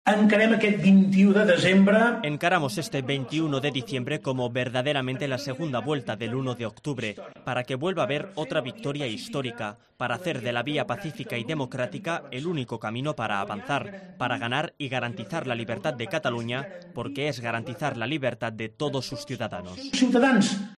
PUIGDEMONT INTERVINIENDO POR VIDEOCONFERENCIA EN EL ACTO DE JUNTS PER CATALUNYA EN SANT JULIÀ DE RAMIS. EFE